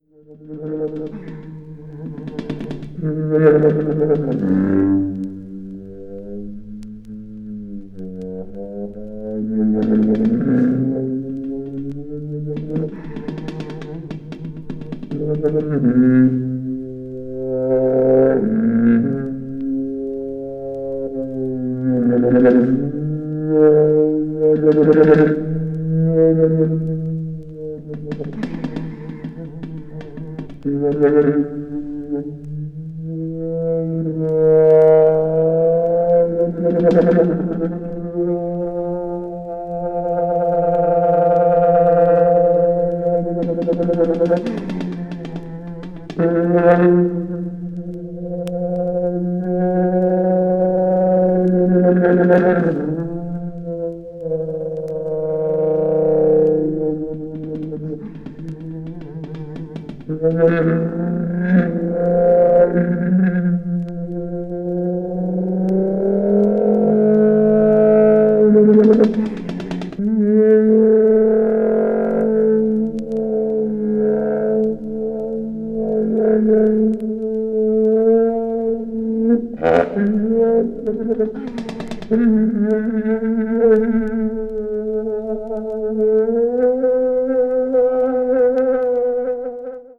media : EX-/EX-(薄いスリキズによるわずかなチリノイズ/一部軽いチリノイズが入る箇所あり)